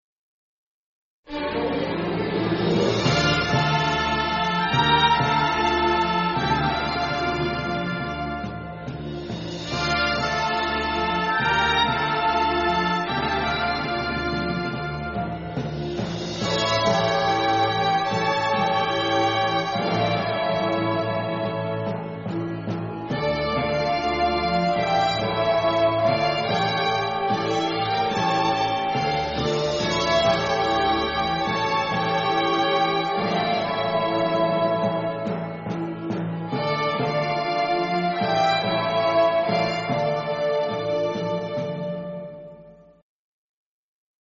مرثیه بی‌کلام